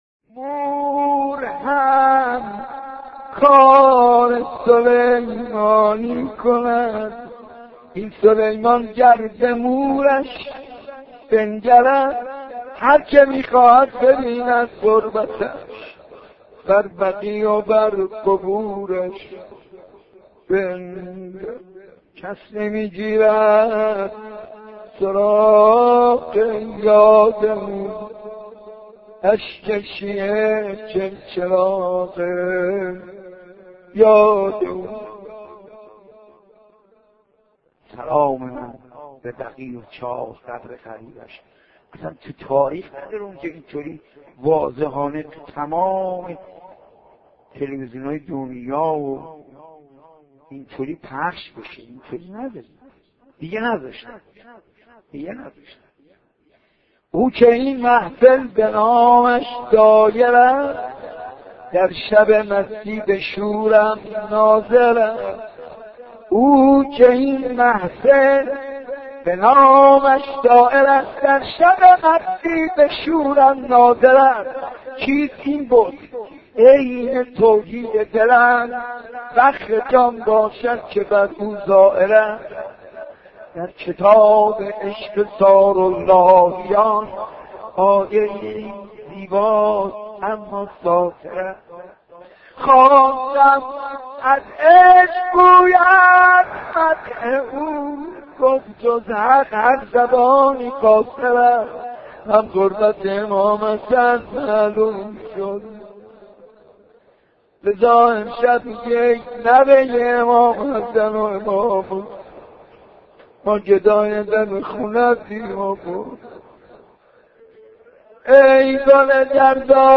• دانلود شهادت امام محمد باقر حاج منصور, مداحی حاج منصور ارضی شهادت امام باقر, نوای عرش, شهادت امام باقر حاج منصور ارضی